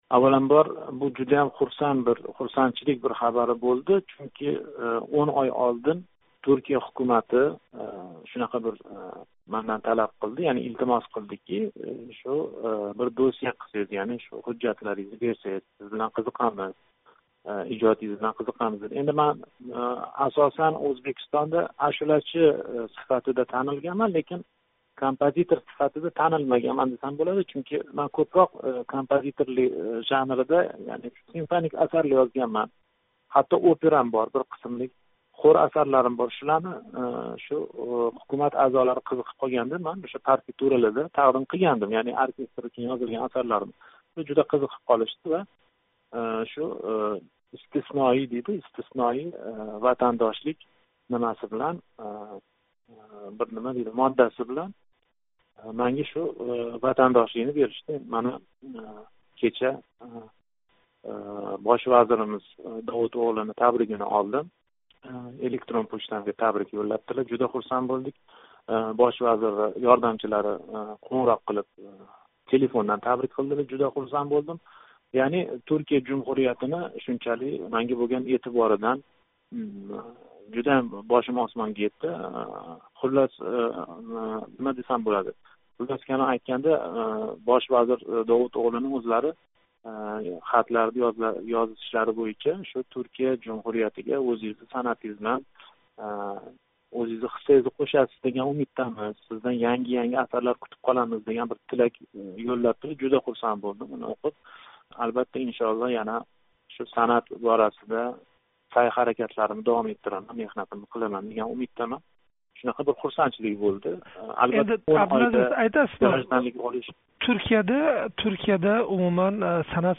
Абдулазиз Карим билан суҳбат